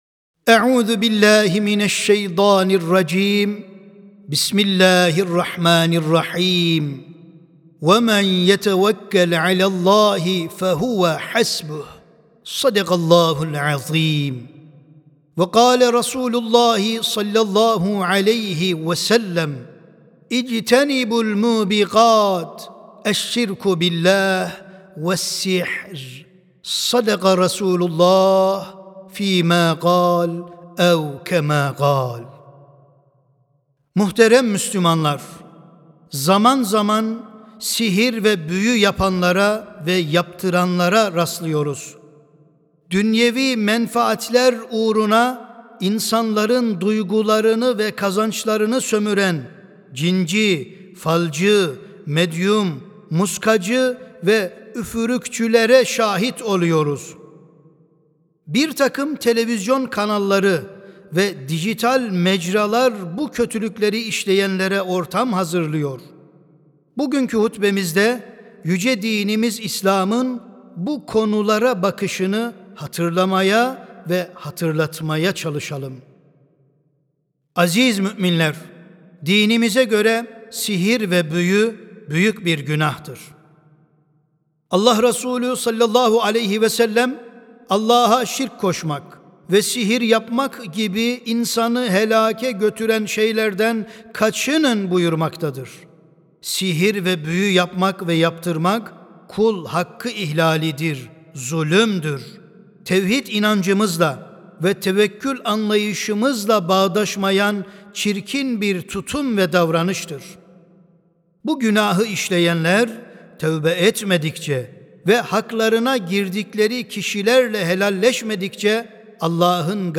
Hutbeler
Sesli Hutbe (Gaybın Bilgisi Yalnızca Allah'a Aittir).mp3